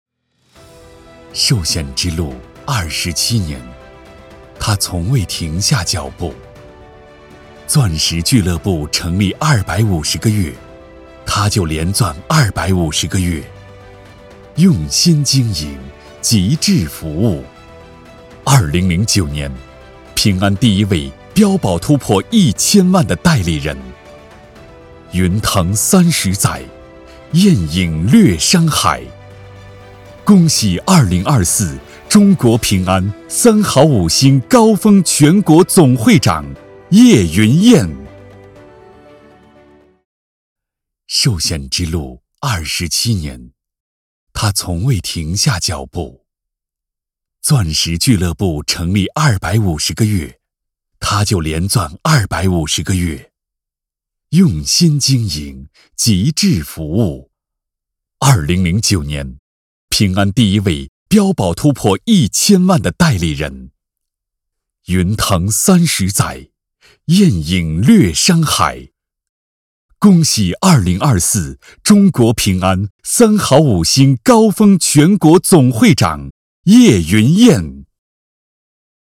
专注高端配音，拒绝ai合成声音，高端真人配音认准传音配音
男30